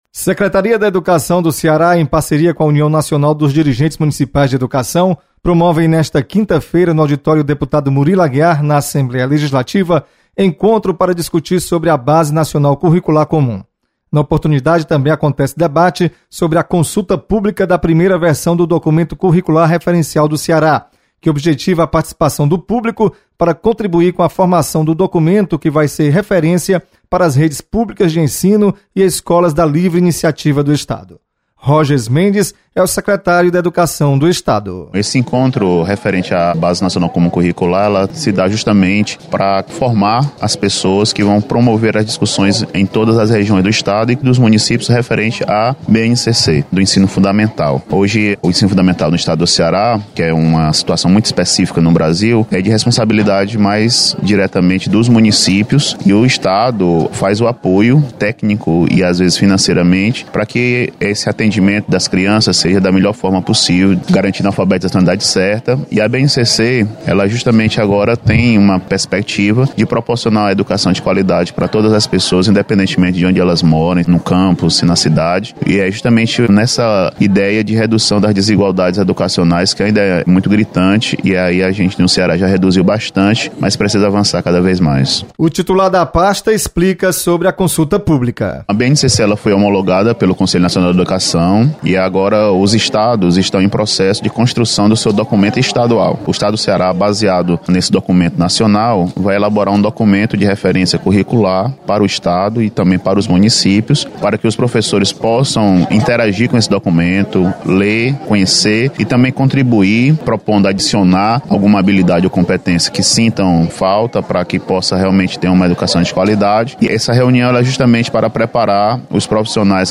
Você está aqui: Início Comunicação Rádio FM Assembleia Notícias Encontro